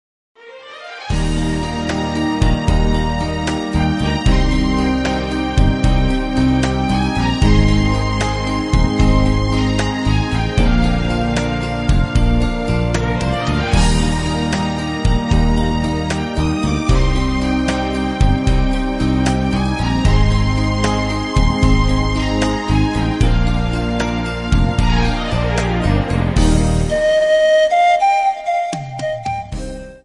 Folk Tangos and Waltzes.